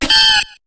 Cri de Macronium dans Pokémon HOME.